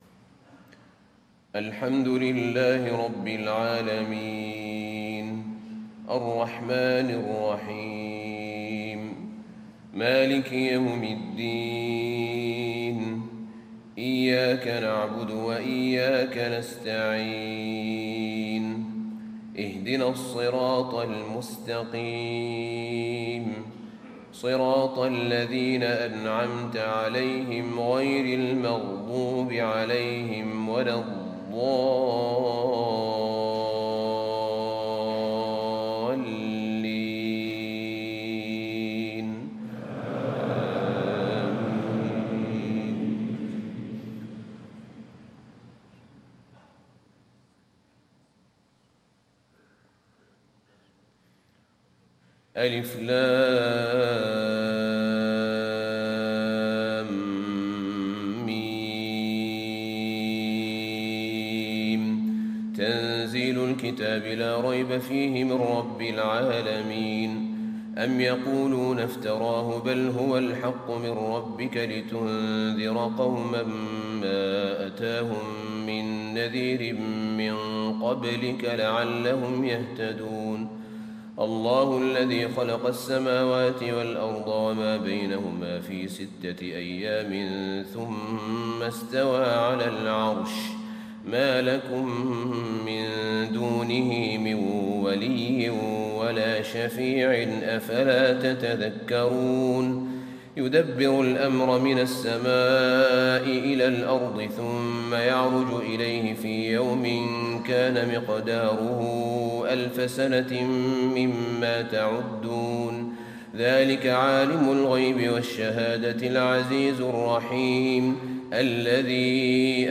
صلاة الفجر 6 شعبان 1437هـ سورتي السجدة و الإنسان > 1437 🕌 > الفروض - تلاوات الحرمين